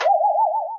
Anime Metal Twang Sound
Category: Sound FX   Right: Commercial